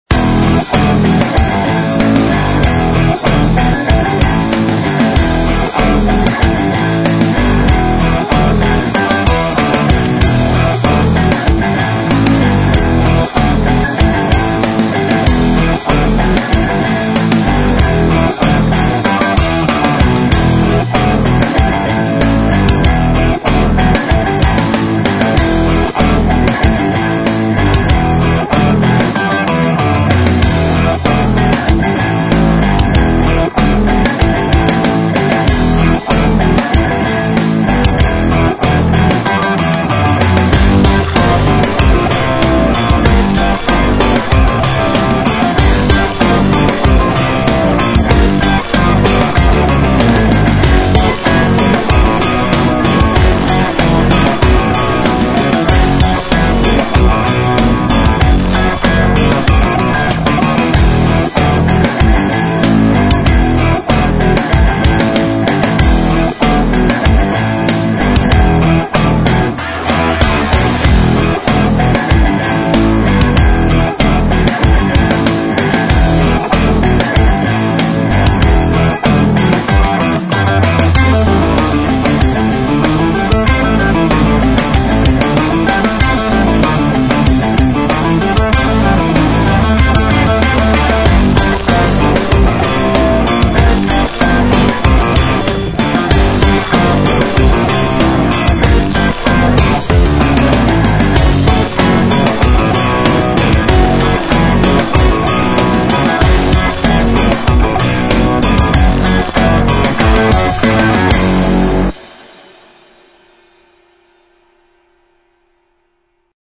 赛道有硬摇滚的氛围，将适合视频 / 广告与赛车，战斗，极限运动，任何地方，你需要酷的金属心情。